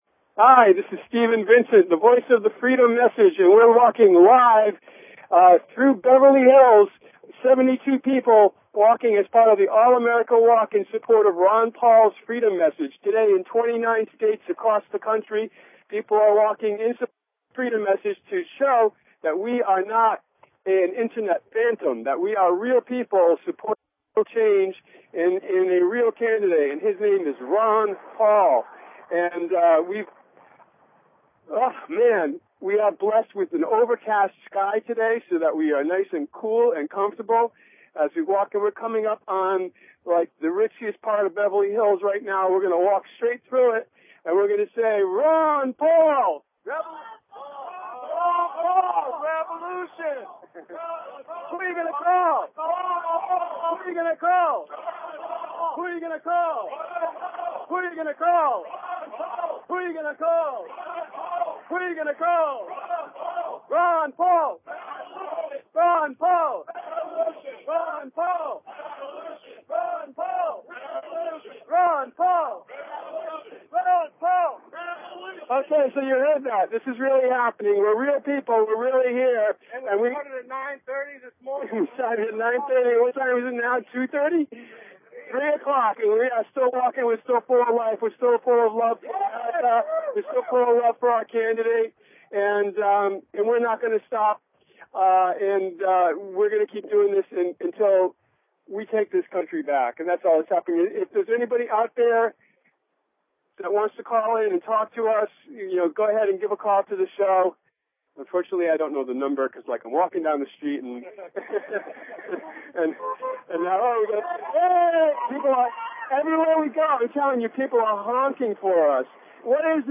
Talk Show Episode, Audio Podcast, Ron_Paul_Radio and Courtesy of BBS Radio on , show guests , about , categorized as
It's a live internet radio call in show for and about the Ron Paul Revolution.